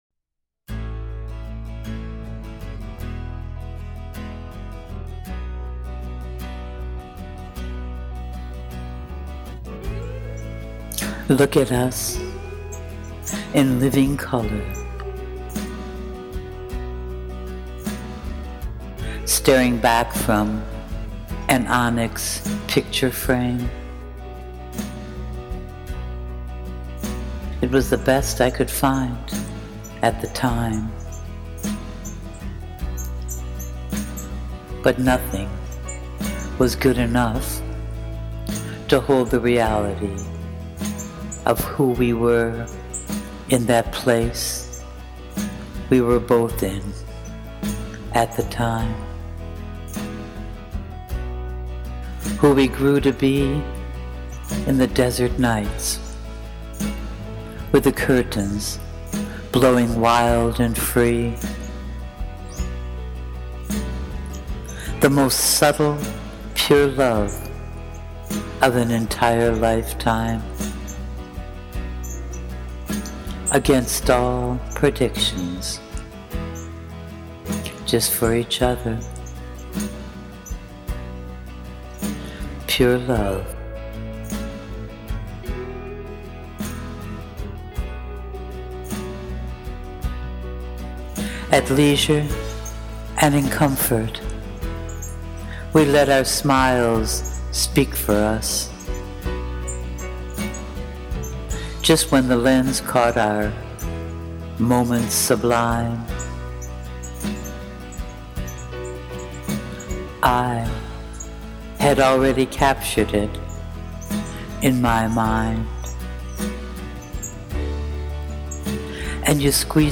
Woah! A beautiful poem, beautifully read and against a soundtrack that features on one of my favourite albums!!! 😁😁😍
Wow ! This is so beautiful and hypnotic.